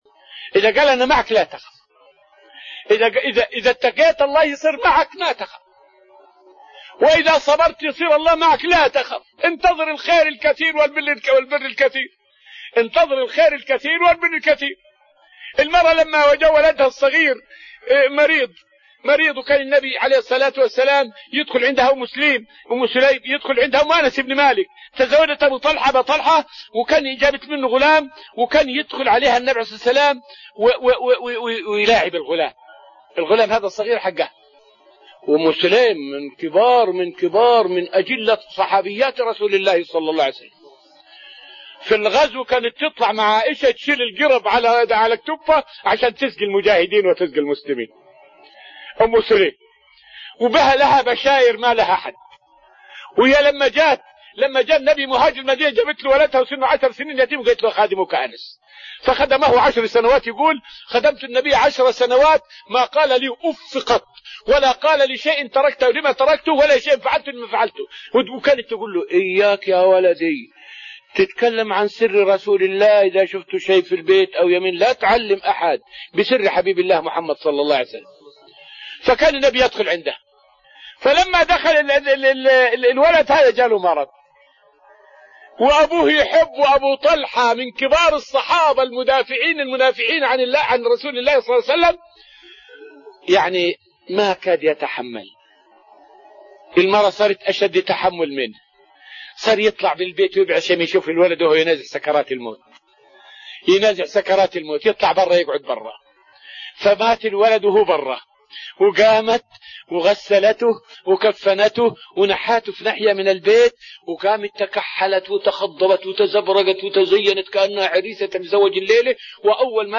فائدة من الدرس العاشر من دروس تفسير سورة الأنفال والتي ألقيت في رحاب المسجد النبوي حول قصة صبر أم سليم وبركة دعاء نبينا محمد في ذريتها.